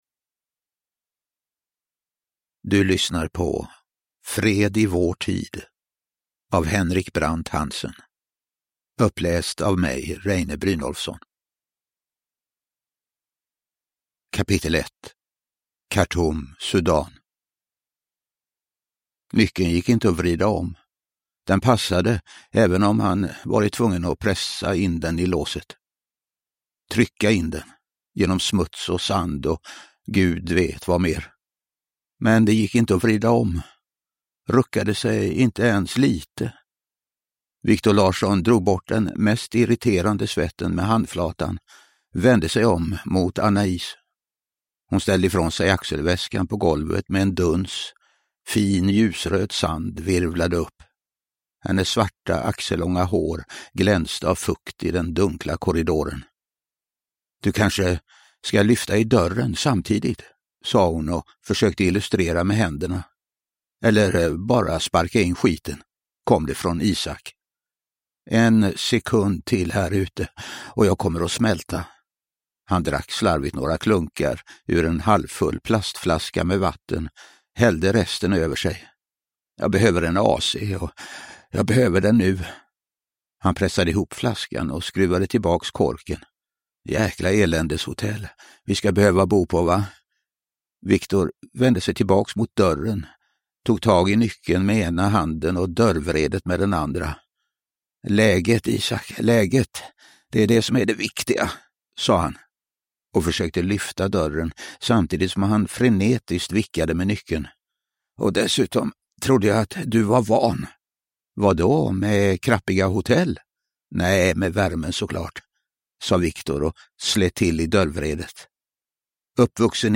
Uppläsare: Reine Brynolfsson
Ljudbok